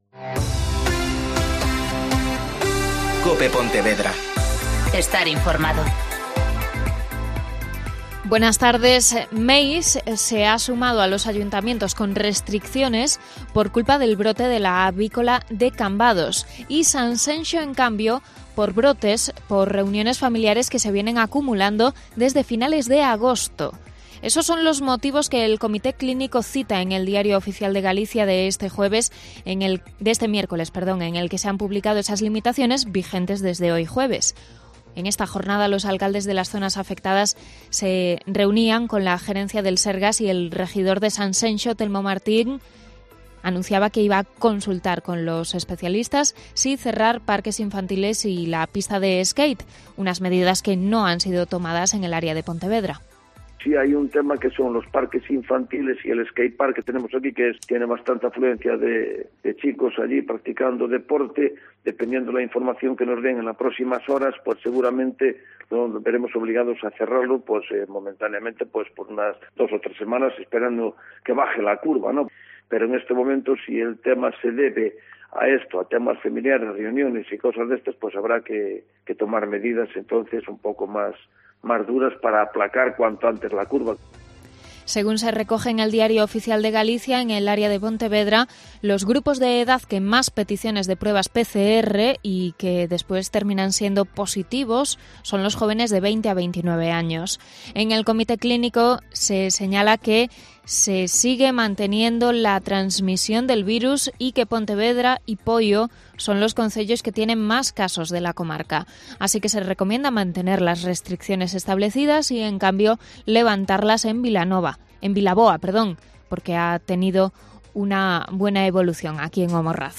Mediodía COPE Pontevedra (Informativo 14-20h)